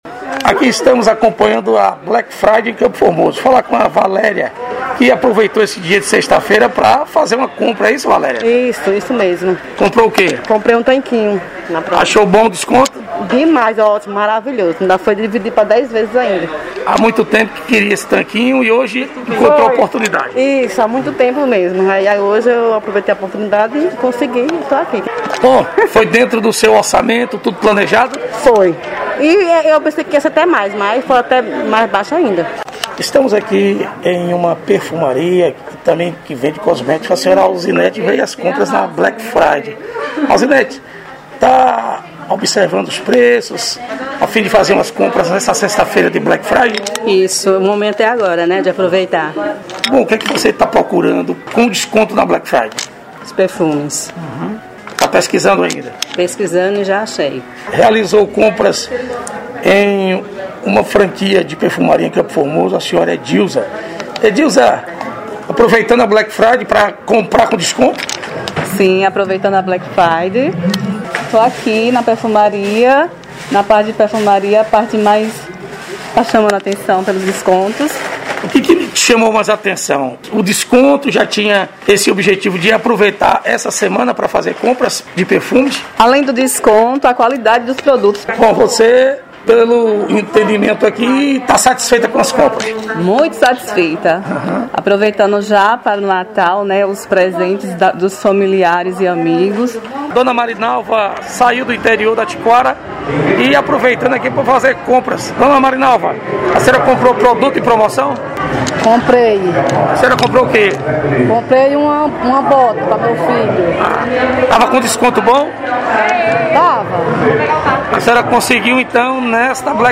Reportagem: Consumidores e comerciantes nas compras Black Friday